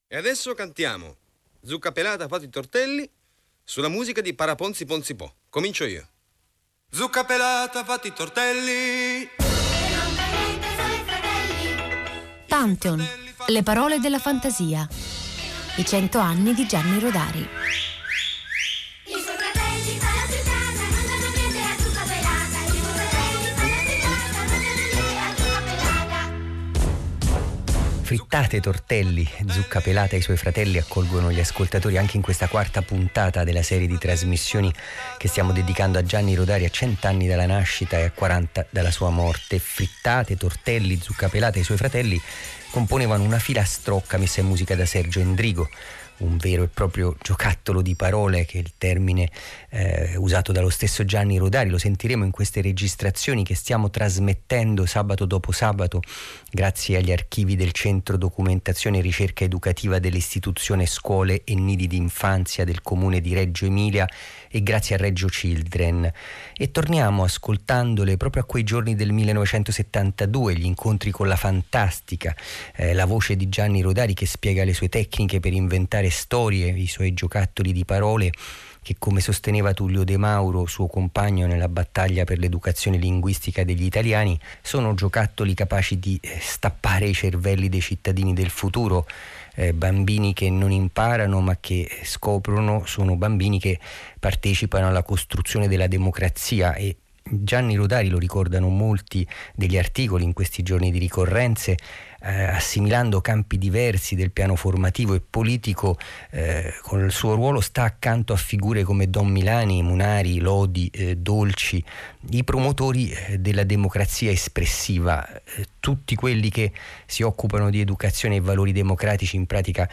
Sabato 25 Aprile 2020 Quarta puntata Le parole della fantasia Cento anni di Gianni Rodari Giocattoli di parole – Quarta puntata Prendete carta e penna e riaprite i vostri taccuini lasciandovi guidare dalla voce di Gianni Rodari che continua a proporre i suoi esercizi di fantastica giocando con le parole, mischiando i titoli di giornale, prendendo spunto da versi celebri e rovesciandoli in nonsense, scrivendo storie servendosi di bigliettini su cui vengono scomposte e ricomposte.